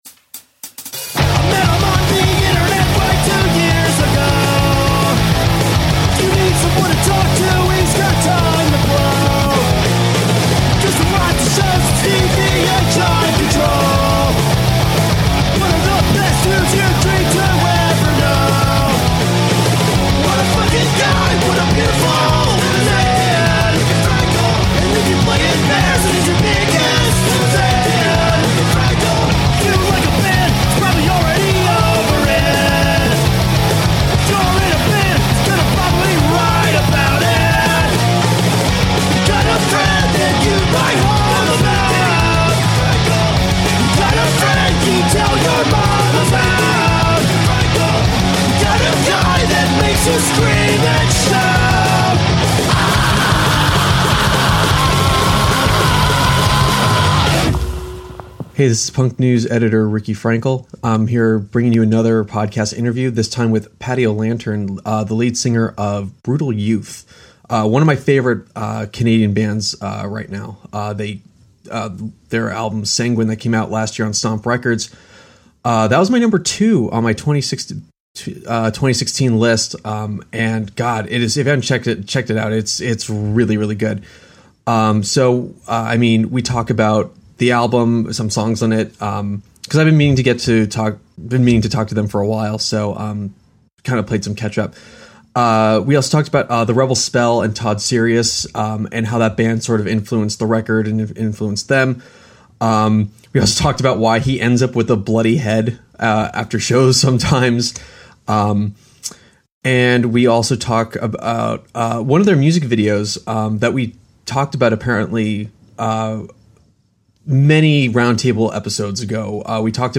Bonus - Interview with Brutal Youth